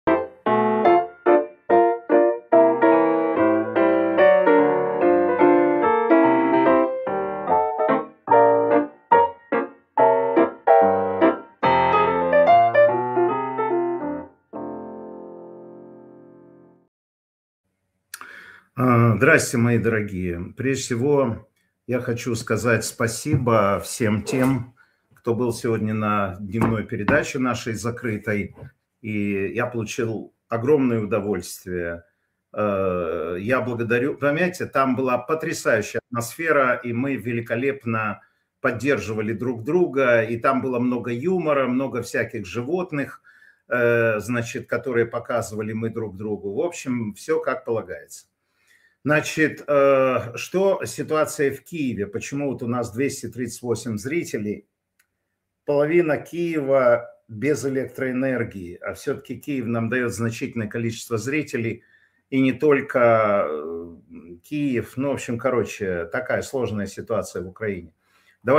Матвей Ганапольскийжурналист
Информационно-аналитическая программа Матвея Ганапольского